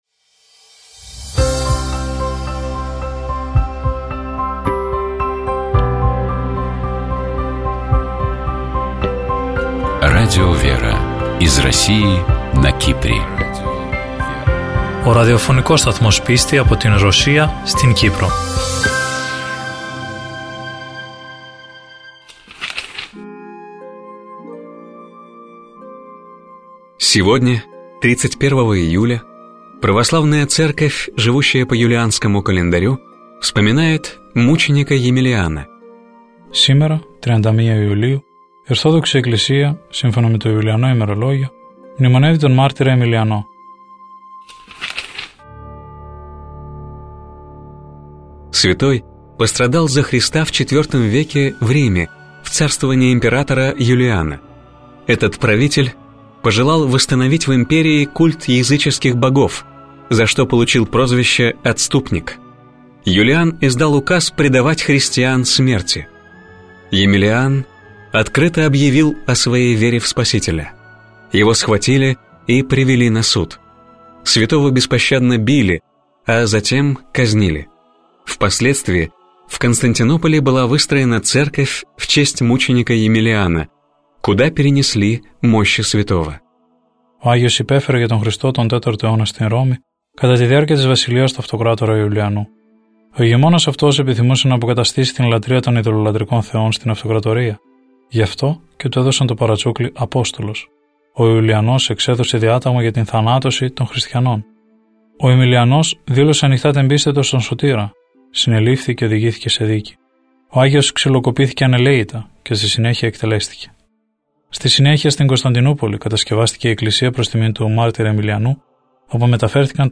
По благословению митрополита Лимассольского Афанасия (Кипрская Православная Церковь) в эфире радио Лимассольской митрополии начали выходить программы Радио ВЕРА. Популярные у российского слушателя программы переводятся на греческий язык и озвучиваются в студии Радио ВЕРА: «Православный календарь», «Евангелие день за днем», «Мудрость святой Руси», «ПроСтранствия», «Частное мнение» и другие.